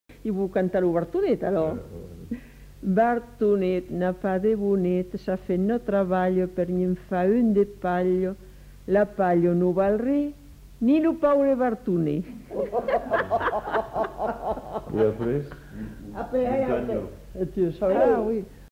Aire culturelle : Haut-Agenais
Genre : forme brève
Effectif : 1
Type de voix : voix de femme
Production du son : récité
Classification : formulette